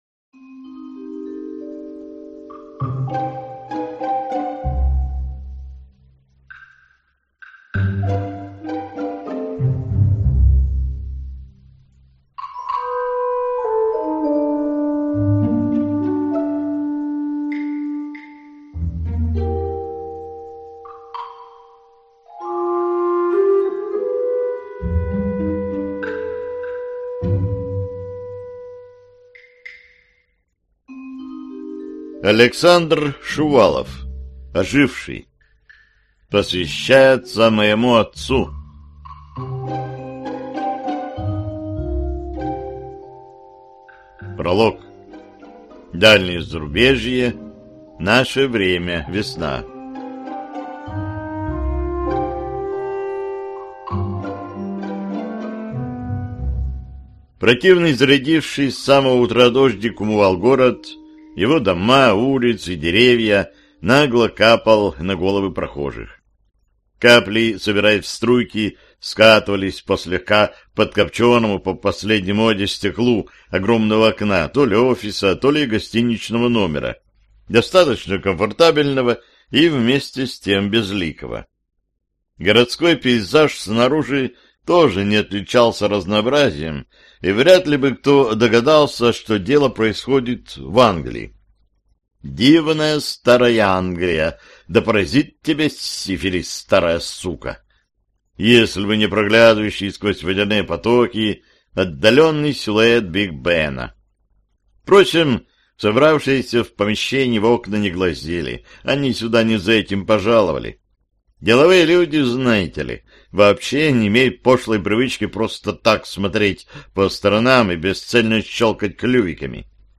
Аудиокнига Оживший | Библиотека аудиокниг